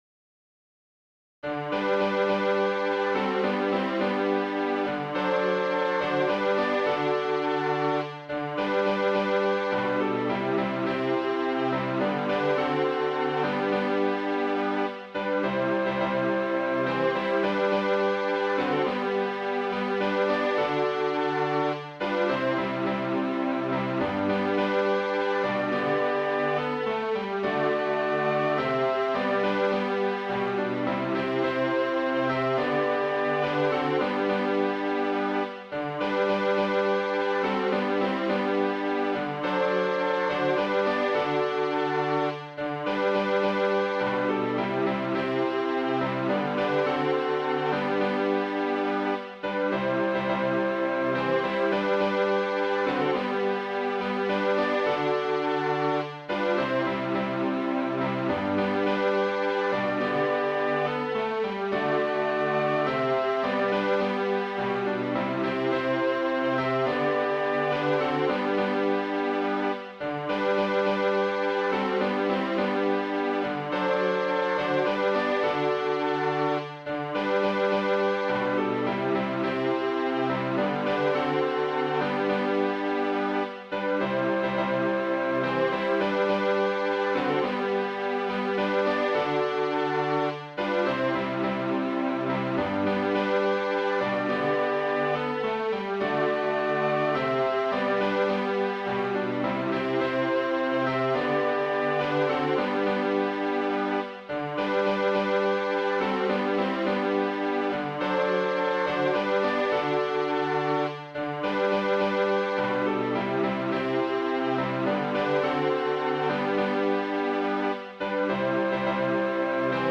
Midi File, Lyrics and Information to The Sword of Bunker Hill